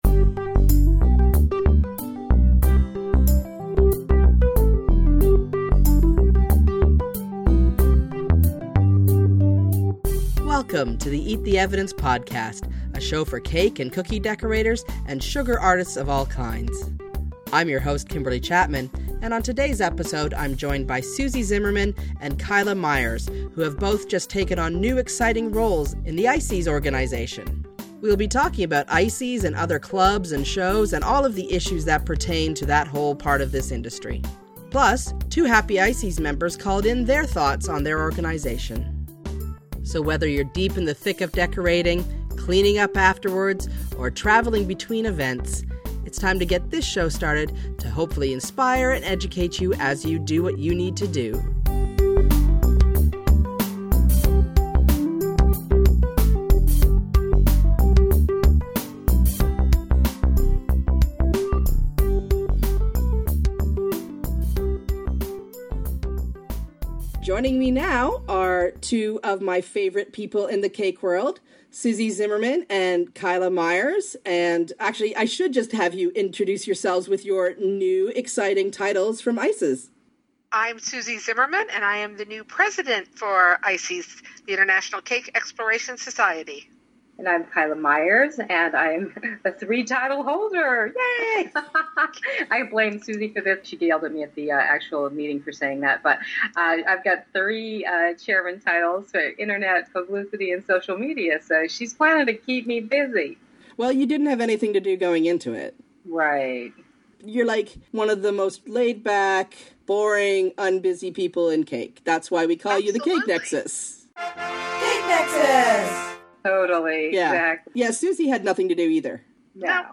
called in with lovely messages about their ICES experiences.
Trumpet Fanfare